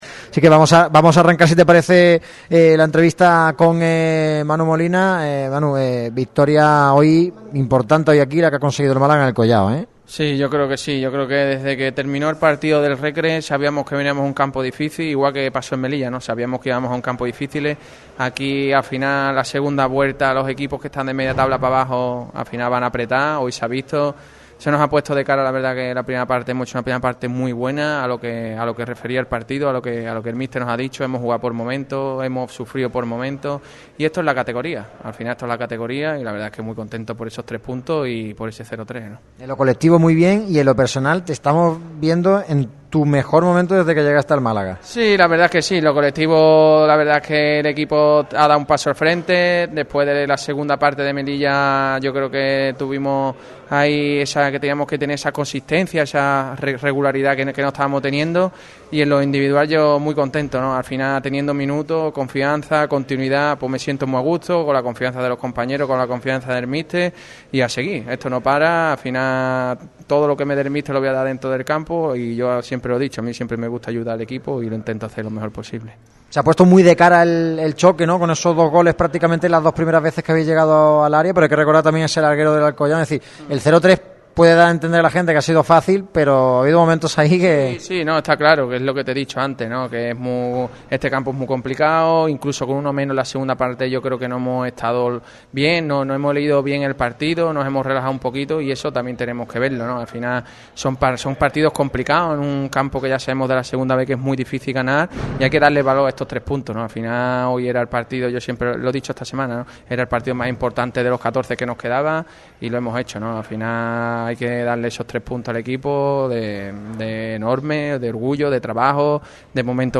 El centrocampista del Málaga, Manu Molina, ha atendido a Radio Marca Málaga y diferentes medios de Alcoy en zona mixta. El jugador ha destacado la importante victoria del equipo en un estadio muy complicado como El Collao: «Son más que tres puntos».